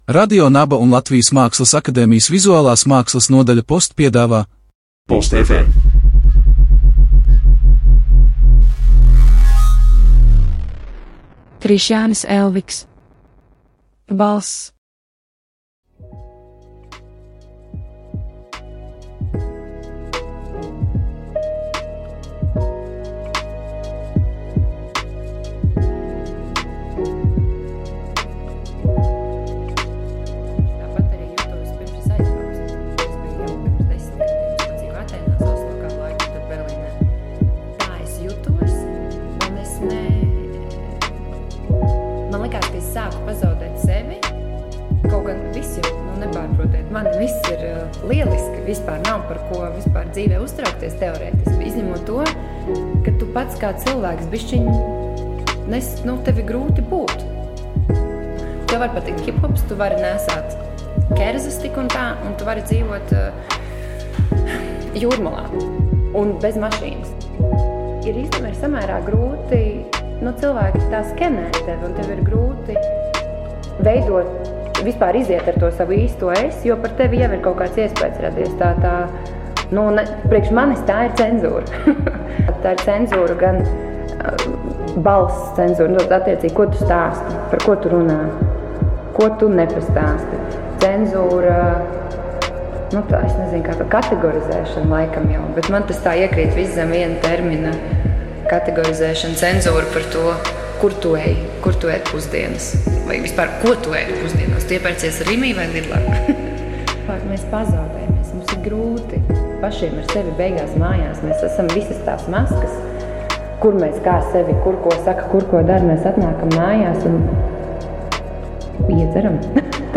Audio performancē "Balss" izskan dažādu cilvēku dokumentālie stāsti, kas atklāj un neatklāj personīgus notikumus, pieredzes, kuros performances dalībnieks sevi pakļāvis pašcenzūrai.